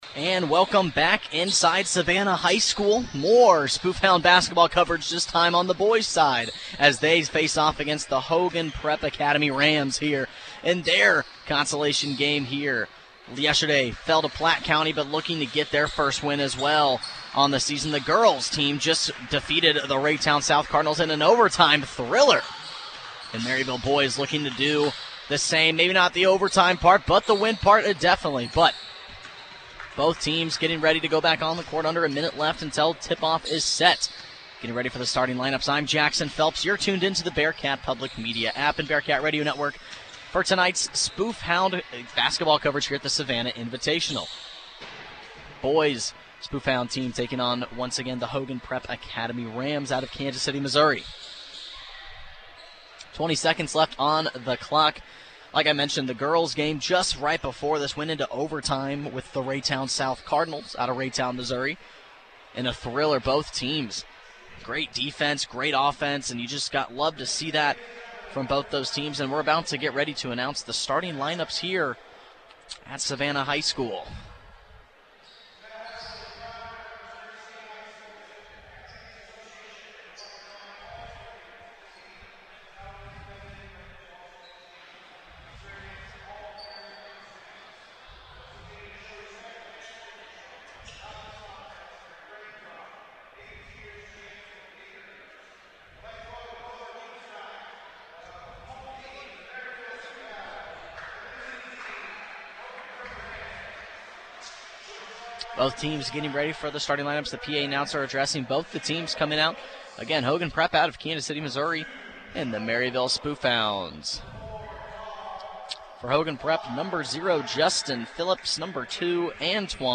Local Sports
Game | Basketball